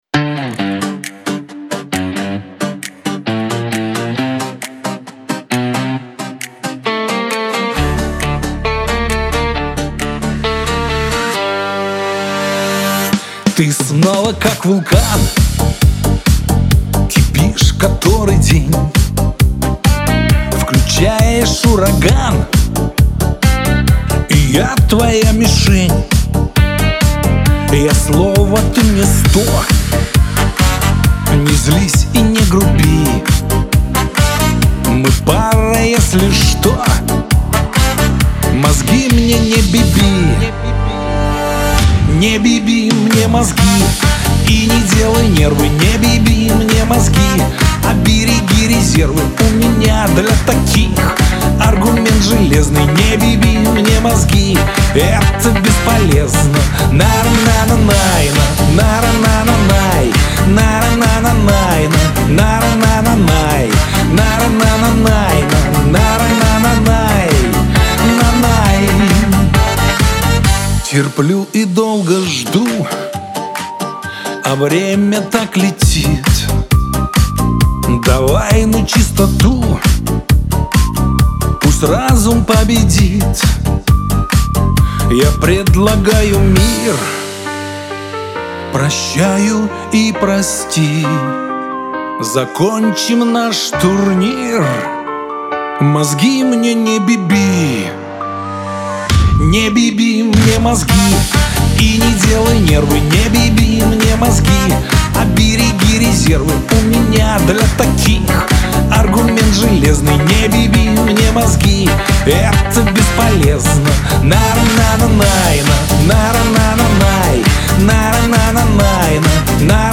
диско
pop
эстрада